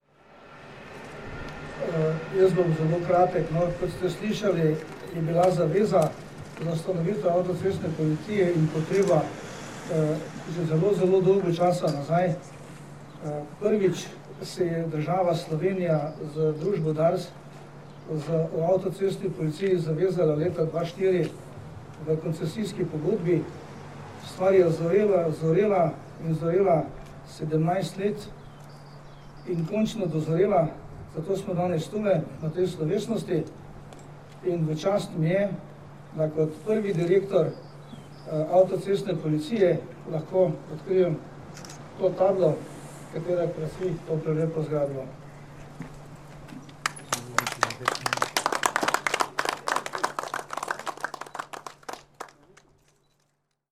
Dogodka ob otvoritvi UAP so se v Postojni, kjer je sedež novoustanovljene uprave, danes, 31. marca 2021, iz MNZ in Policije udeležili minister za notranje zadeve Aleš Hojs, namestnik generalnega direktorja policije Tomaž Pečjak in direktor uprave Andrej Jurič.
Zvočni posnetek izjave direktorja Uprave avtocestne policije Andreja Juriča